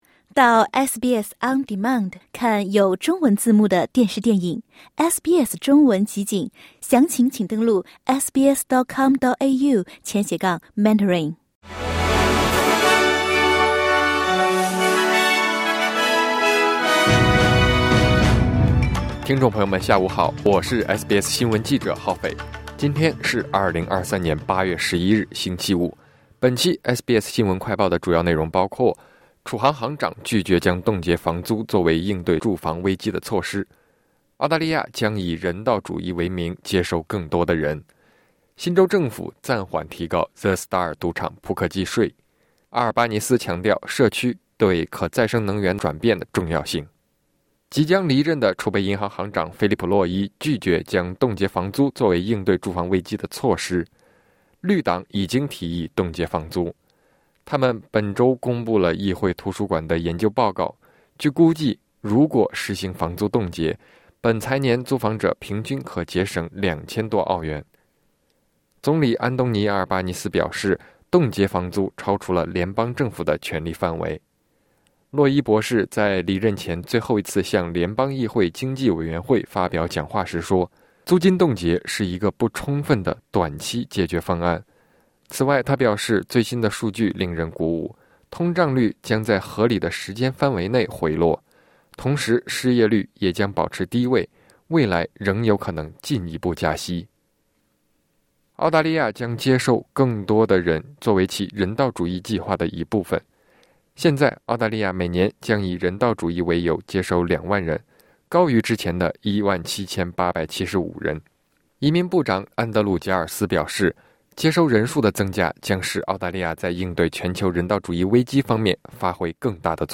【SBS新闻快报】联邦储备银行行长拒绝将冻结房租作为应对住房危机的措施